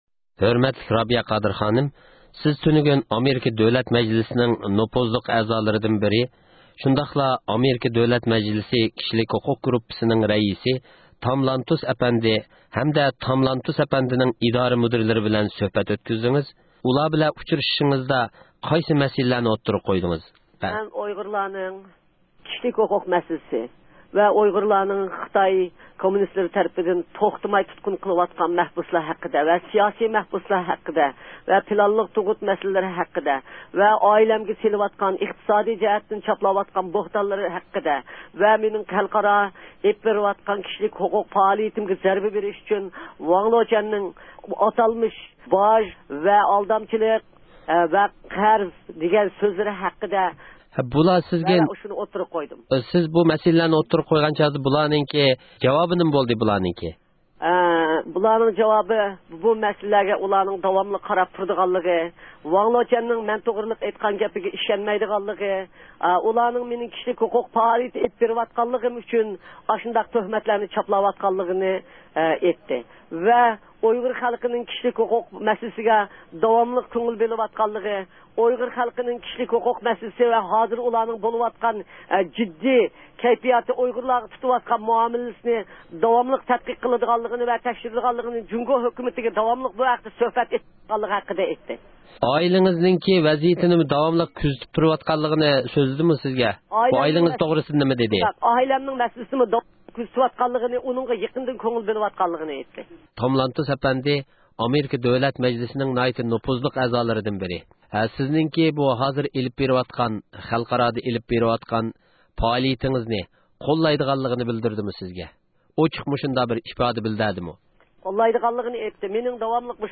رابىيە قادىر خانىمنى زىيارەت قىلىپ، بۇ ئۇچىرىشىشلارنىڭ مەقسىدى ۋە ئۇچرىشىشلار جەريانىدا قانداق مەسىلىلەرنىڭ ئوتتۇرىغا قويۇلغانلىقى ھەققىدە رابىيە قادىر خانىم بىلەن سۆھبەت ئۆتكۈزدى.